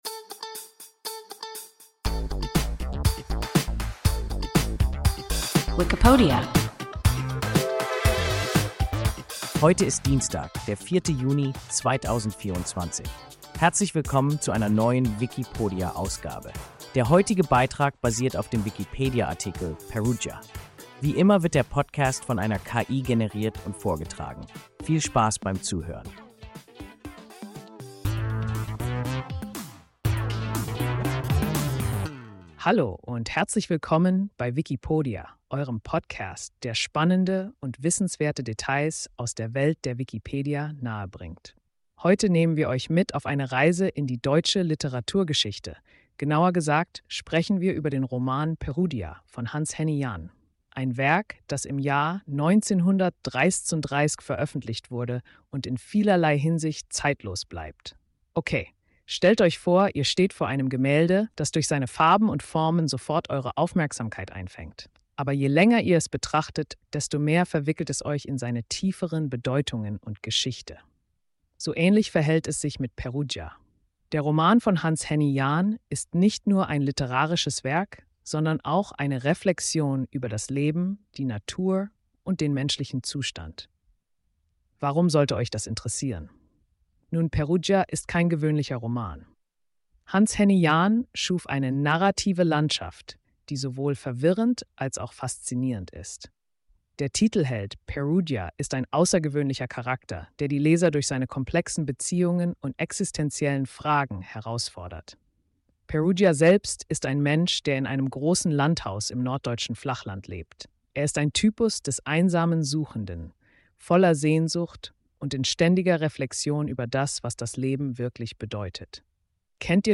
Perrudja – WIKIPODIA – ein KI Podcast